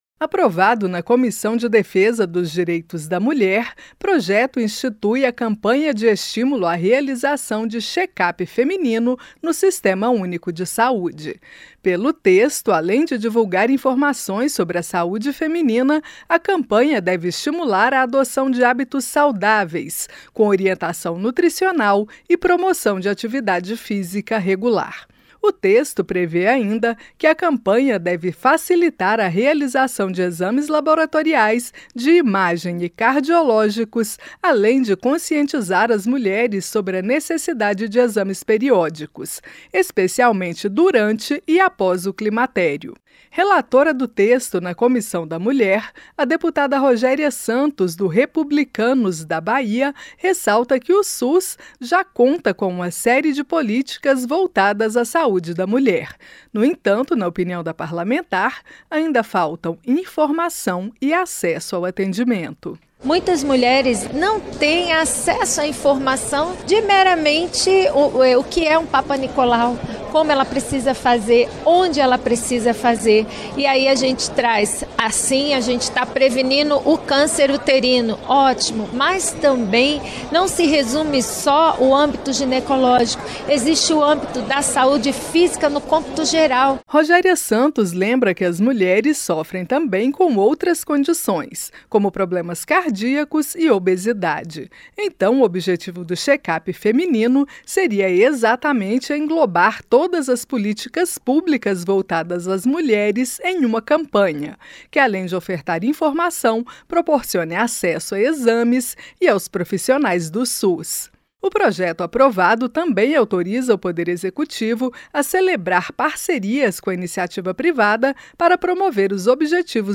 Projeto institui campanha de promoção de saúde da mulher no SUS - Radioagência